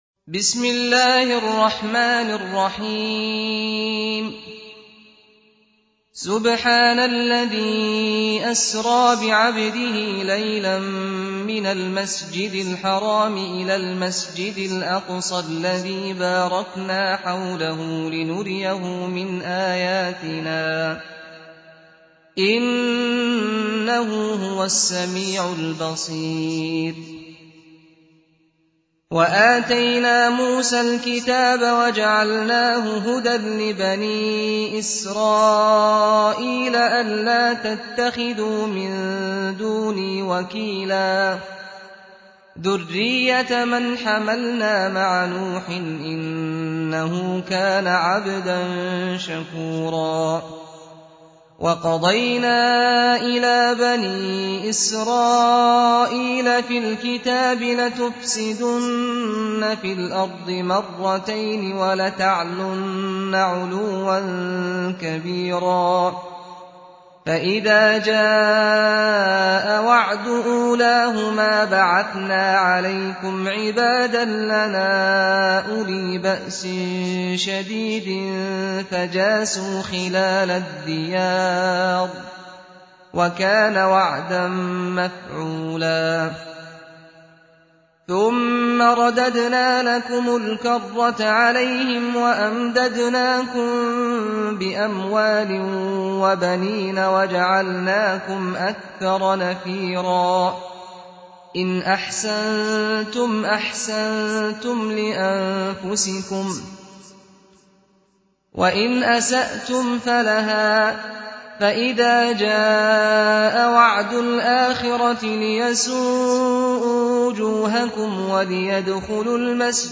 قرائت ترتیل «جزء 15» قرآن كریم با صدای استاد سعد الغامدی | به مدت 50 دقیقه
❖ دانلود ترتیل جزء پانزده قرآن کریم با صدای دلنشین استاد سعد الغامدی | مدت : 50 دقیقه